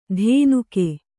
♪ dhēnuke